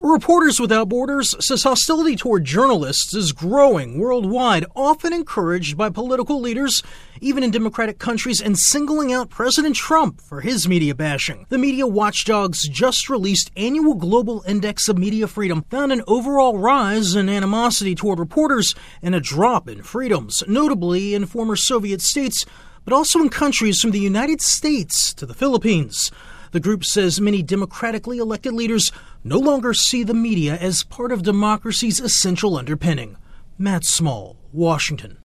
A new report says hostility toward journalists is on the rise. AP correspondent